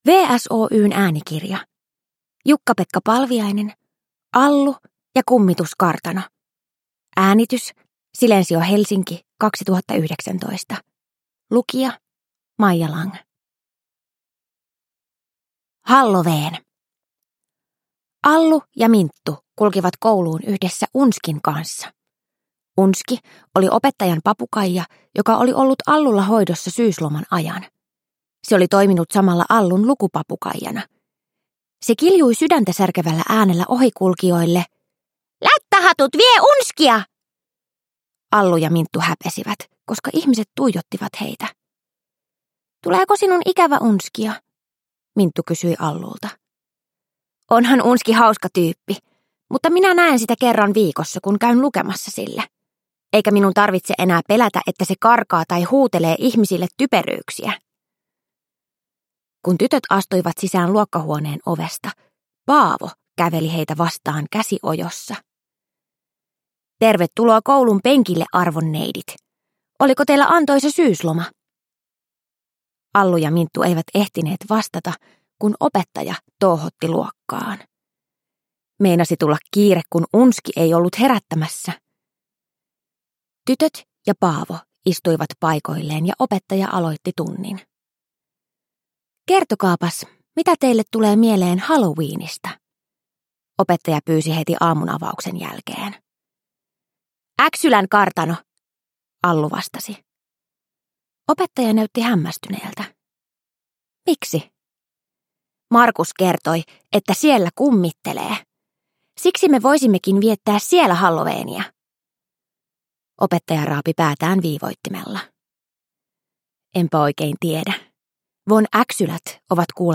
Allu ja kummituskartano – Ljudbok – Laddas ner